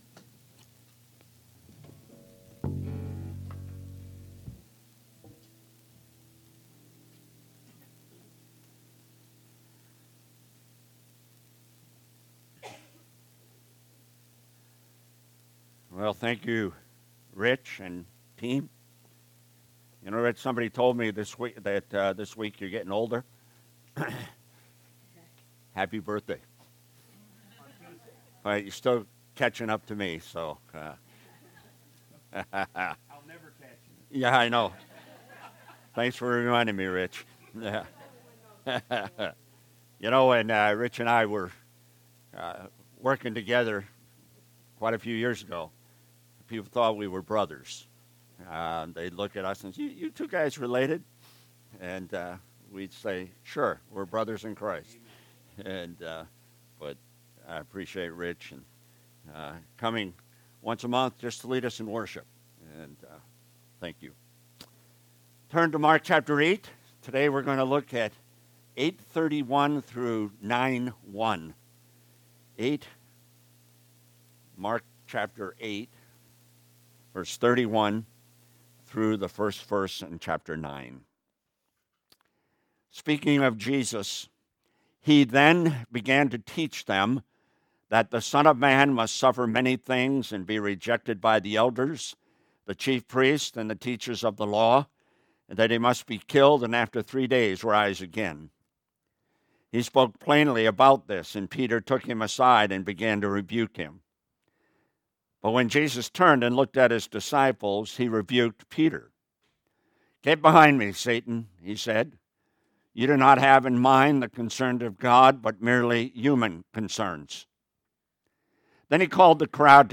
Sermons - Pleasant Prairie Baptist Church